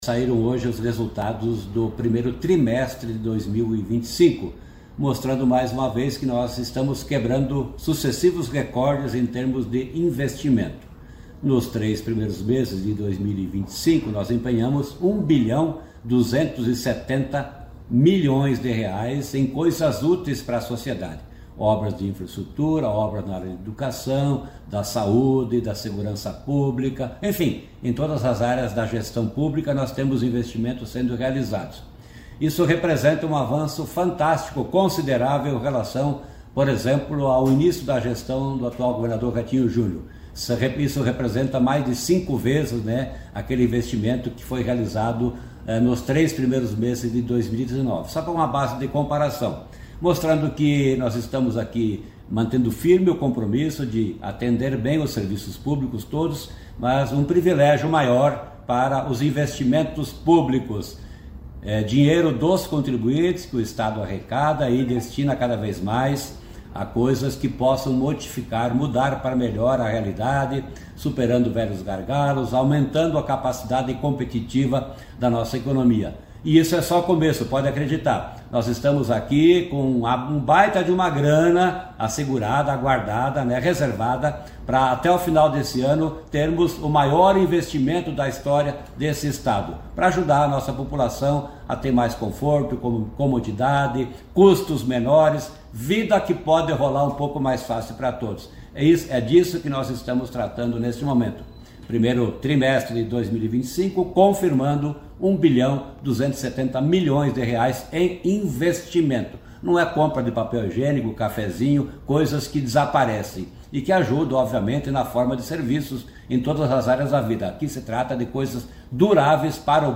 Sonora do secretário Estadual da Fazenda, Norberto Ortigara, sobre os investimentos recordes no Paraná no 1º trimestre